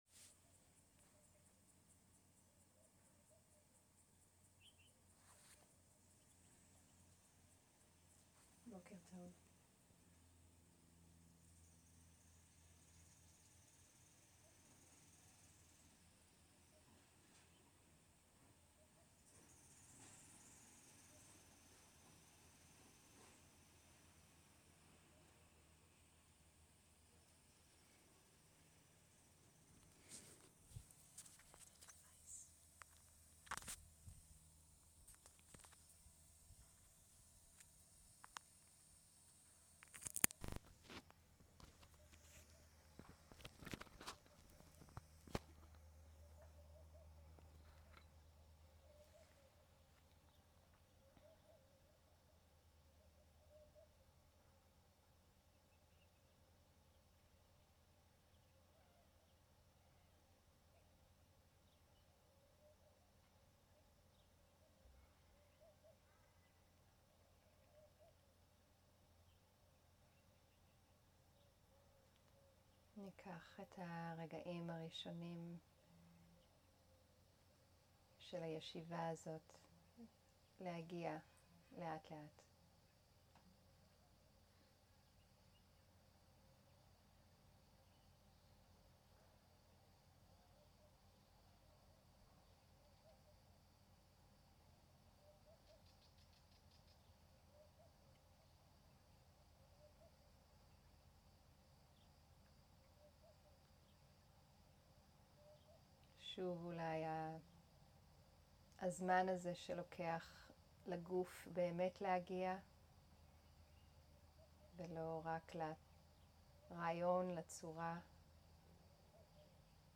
הנחיות מדיטציית בוקר במרחב הפתוח
סוג ההקלטה: שיחת הנחיות למדיטציה
איכות ההקלטה: איכות גבוהה